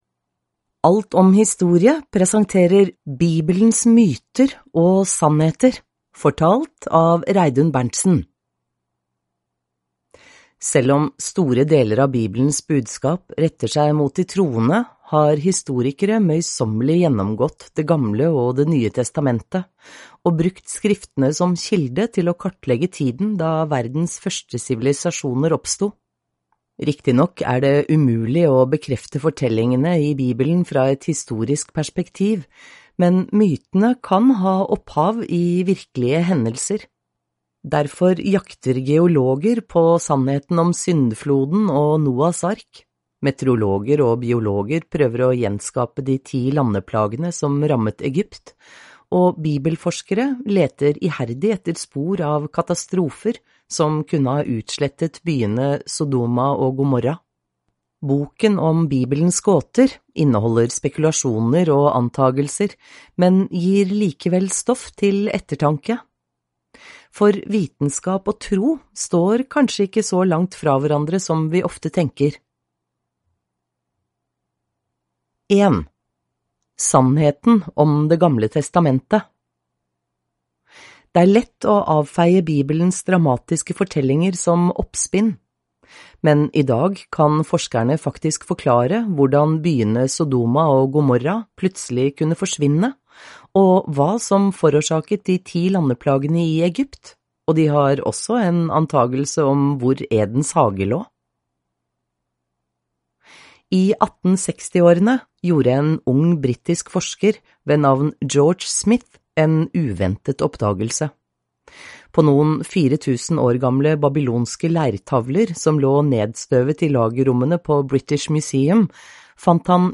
Bibelens myter og sannheter (ljudbok) av All verdens historie